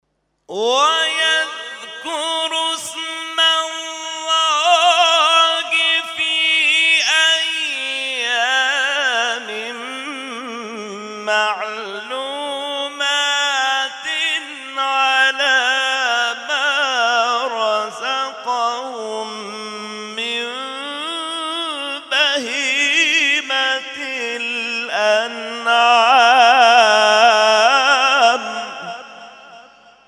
محفل انس با قرآن در آستان عبدالعظیم(ع)+ صوت